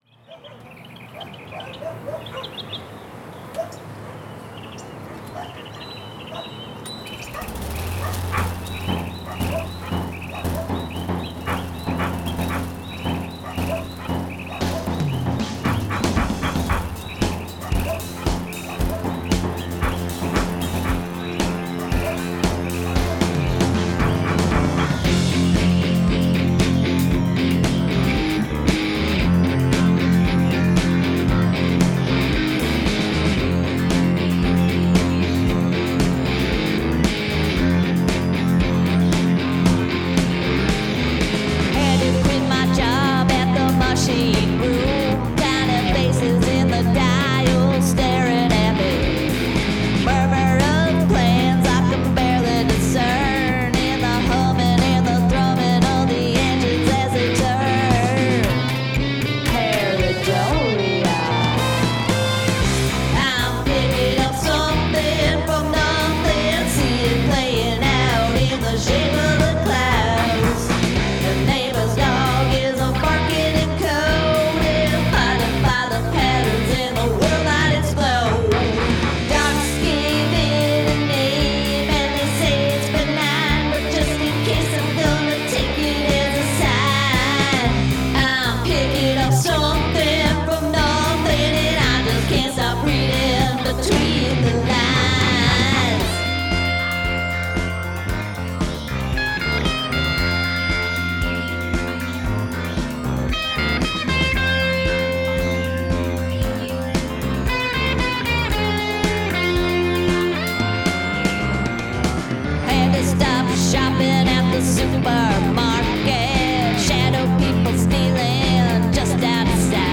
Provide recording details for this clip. Use of field recording